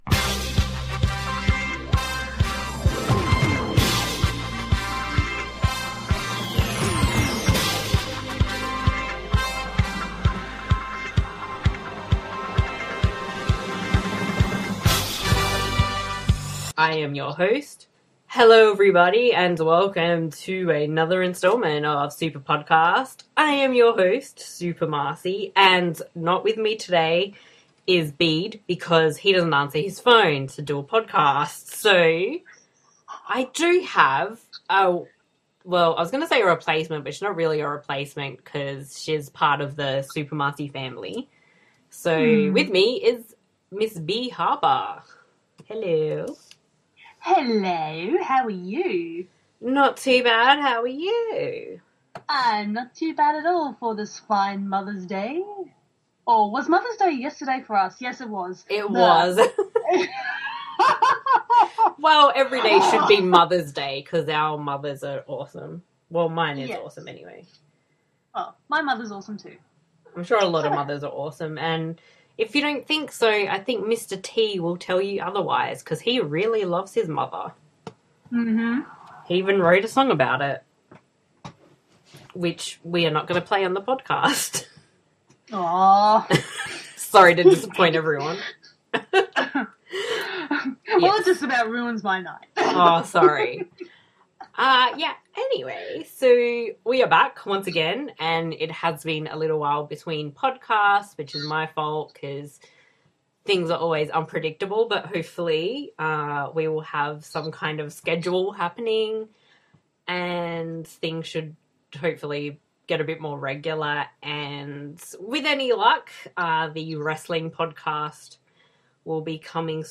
90s Horror Movie Discussion